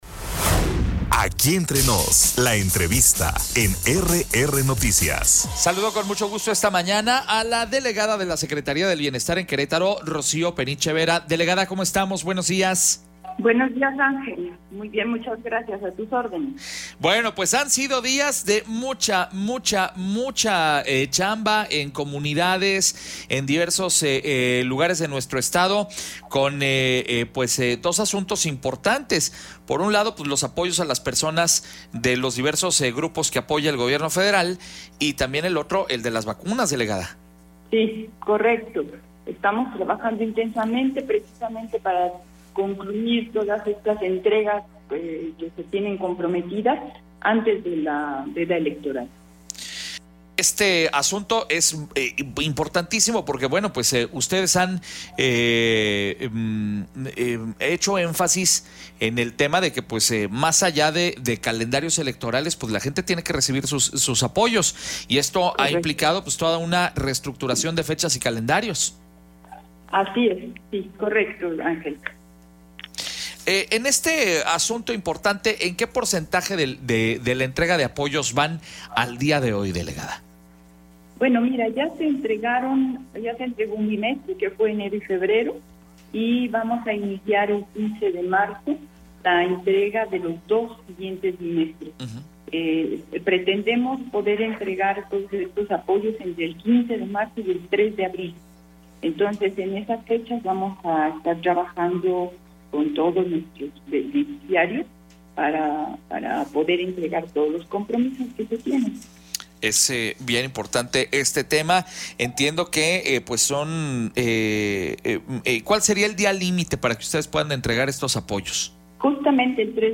Entrevista con Rocío Penicha, delegada de la Secretaría del Bienestar en Querétaro.